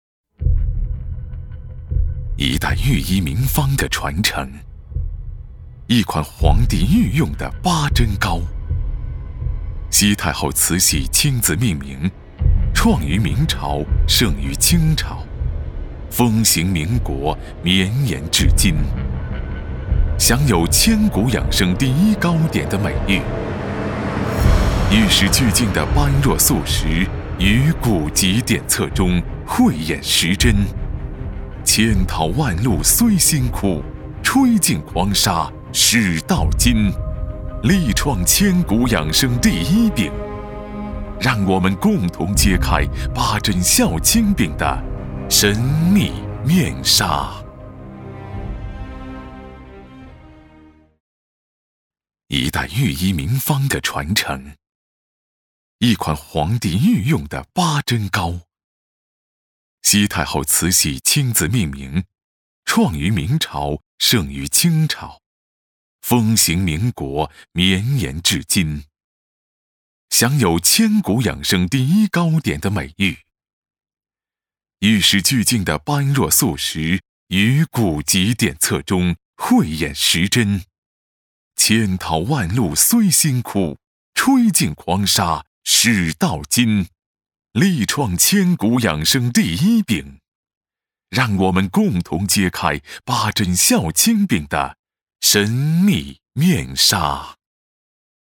央视配音员，拥有国家普通话测试一级甲等证书，声音大气浑厚，擅长专题类，宣传类稿件。
语言：普通话 （140男）
特点：高端大气
风格:浑厚配音